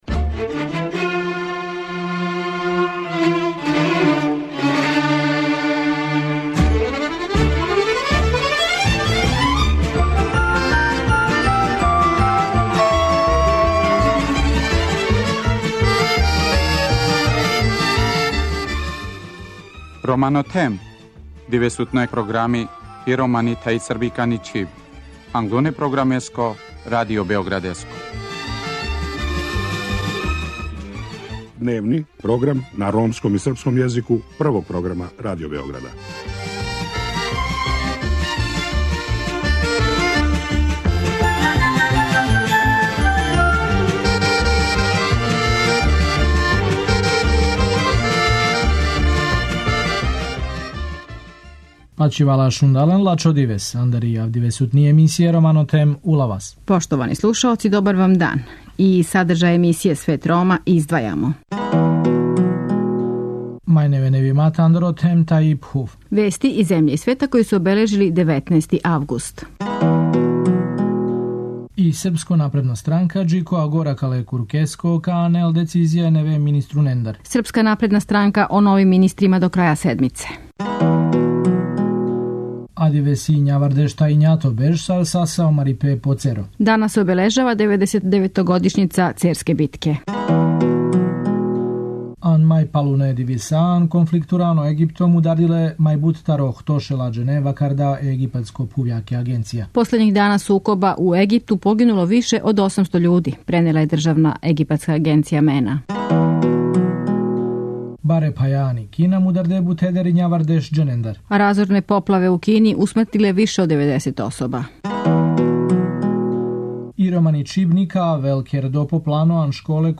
Са помоћницом покрајинског секретара за привреду, запошљавање и равноправност полова, Славицом Денић, разговарамо о активностима војвођанског Сектора за унапређење положаја Рома.